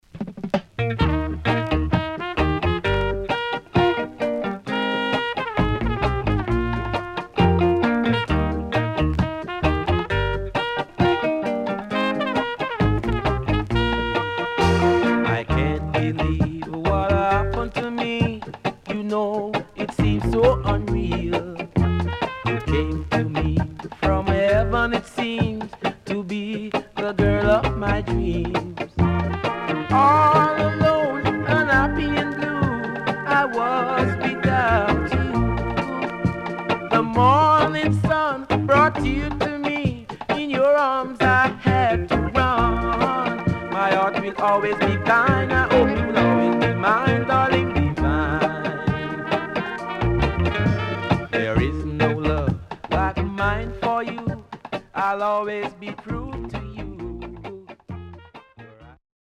いなたいNice Vocal